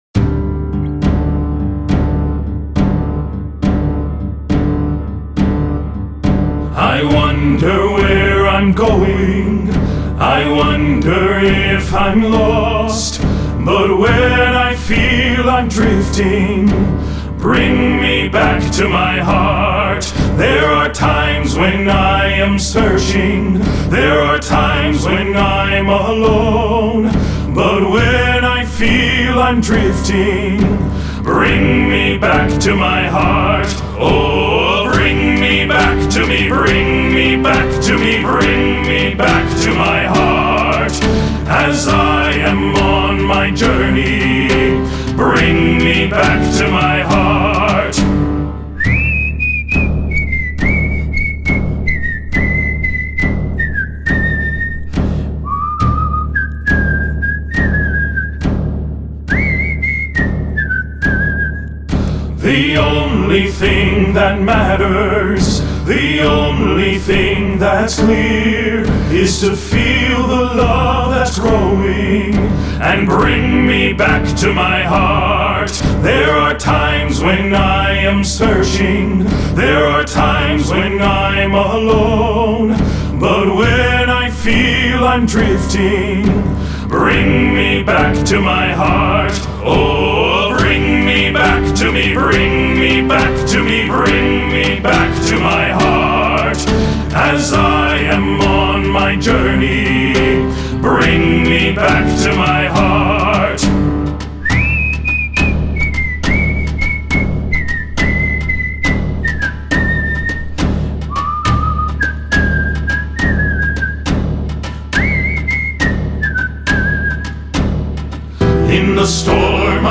Choral Male Chorus
Like a sea shanty
spirits along in a rollicking 6/8